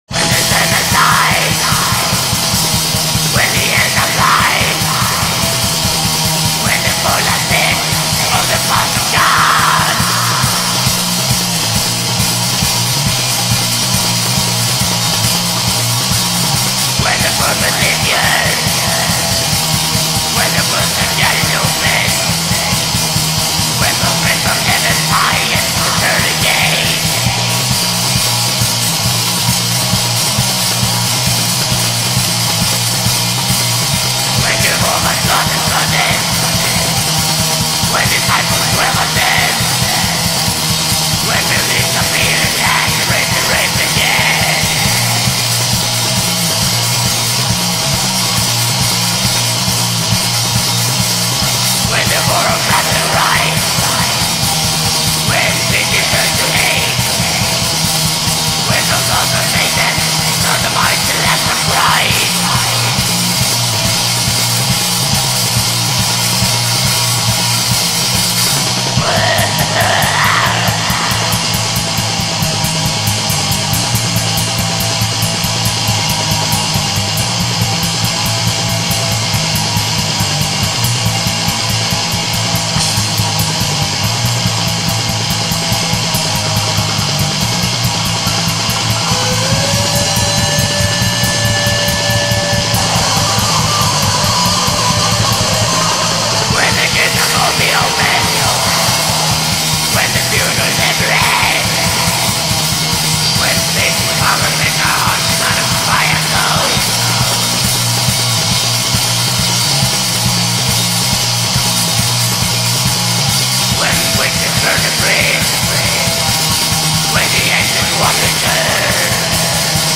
بلک متال